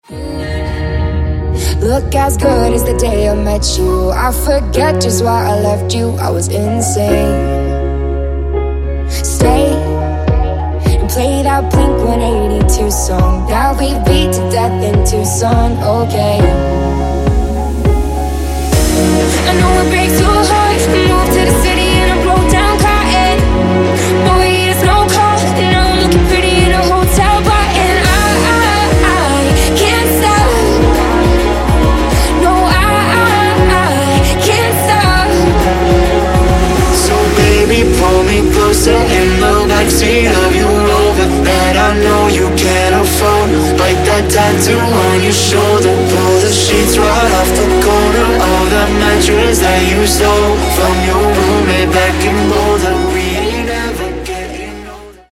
• Качество: 128, Stereo
поп
женский вокал
dance
EDM
vocal